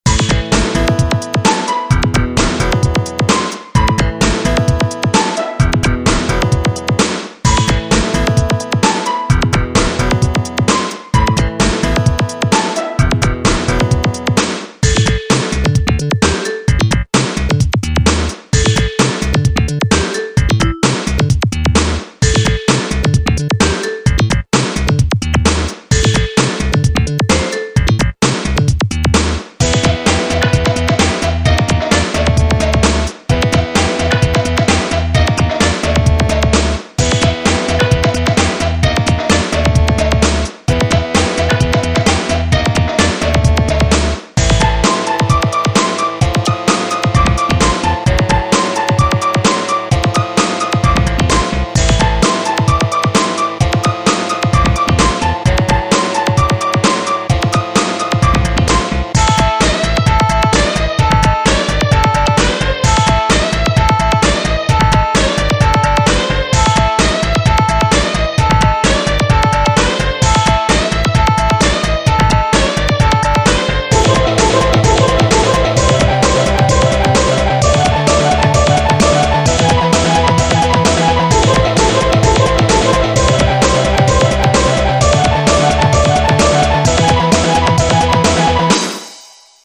제목은 : dark forest *온라인 아케이드게임용 메인 배경 음악이구요 순수히 pc sound card 만을 사용한 GM mid파일 입니다 무한 loop되는 음악입니다.